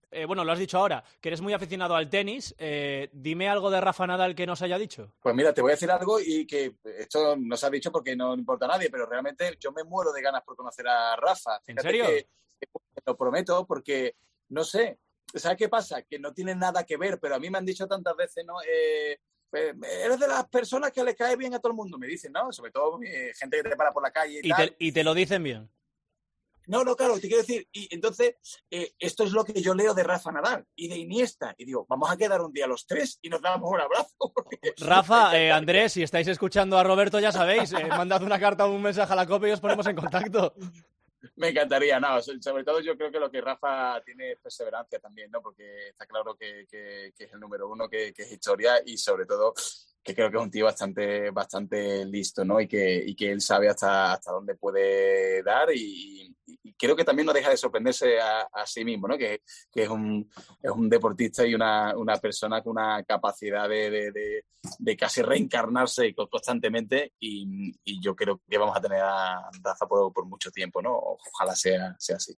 El presentador de 'Pasapalabra' ha pasado por 'La Contraportada' de 'El Partidazo' de COPE donde ha hablado de deporte y de su pasión por el Sevilla